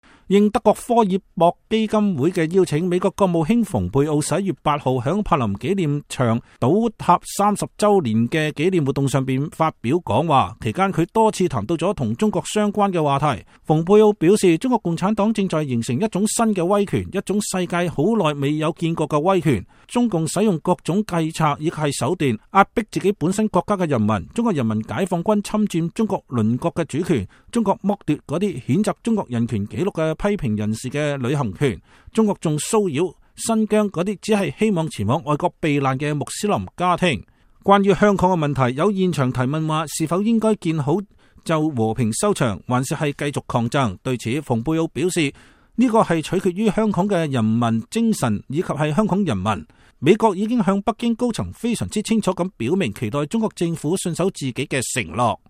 應德國科爾博基金會的邀請，美國國務卿蓬佩奧11月8日在柏林紀念柏林牆倒塌三十週年的紀念活動上並發表講話，期間他多次談到與中國相關的話題。蓬佩奧說，中國共產黨正在形成一種新的威權，一種世界很久沒有見過的威權。